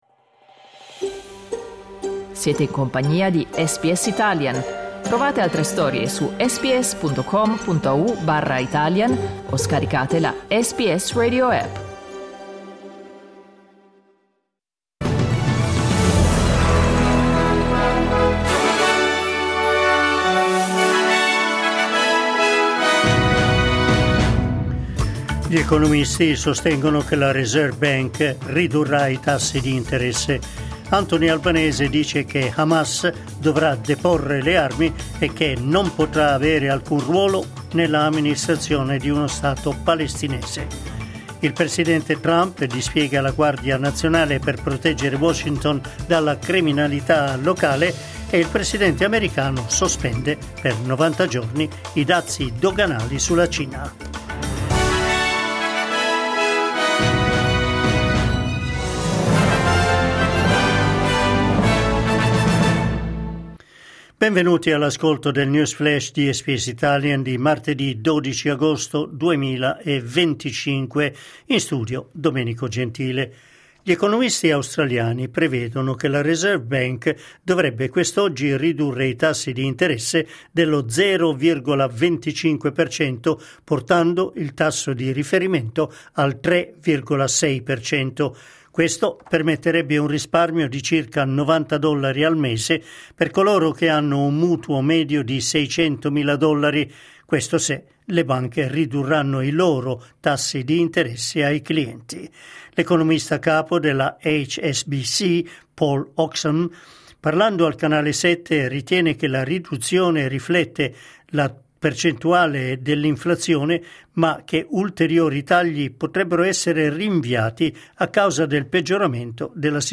L’aggiornamento delle notizie di SBS Italian.